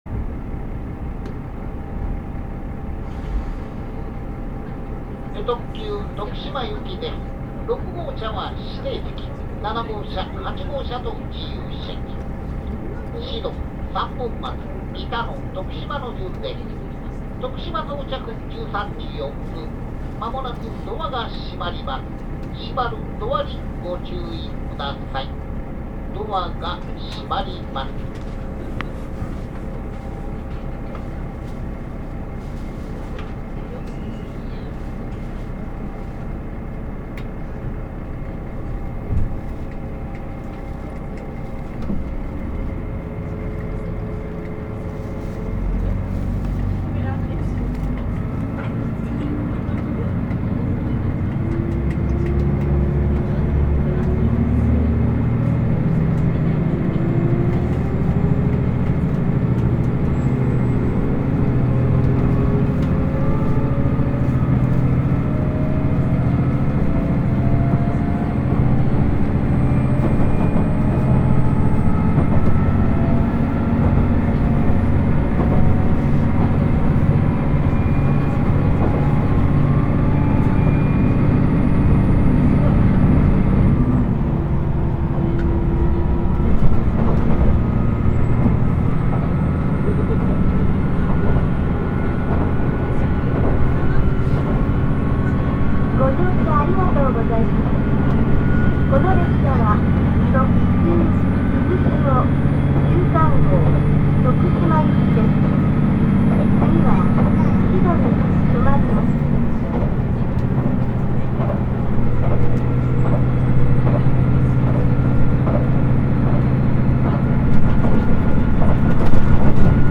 走行音
録音区間：栗林～志度(うずしお13号)(お持ち帰り)